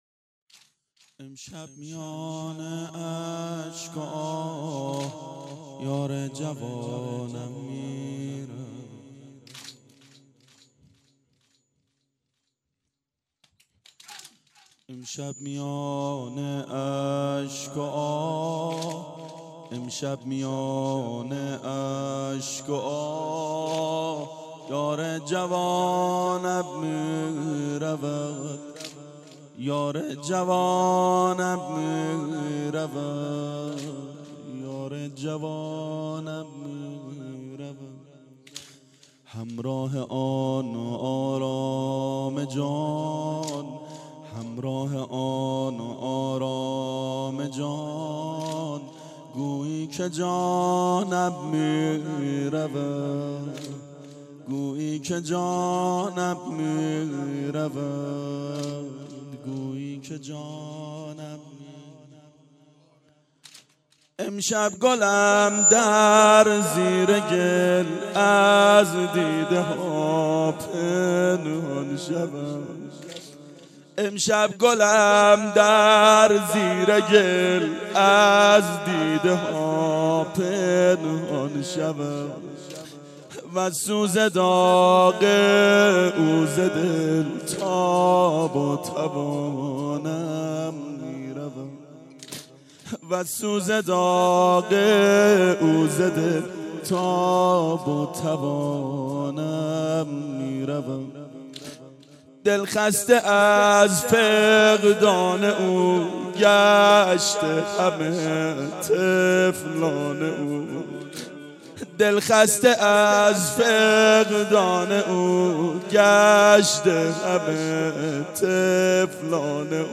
• فاطمیه اول 92 هیأت عاشقان اباالفضل علیه السلام منارجنبان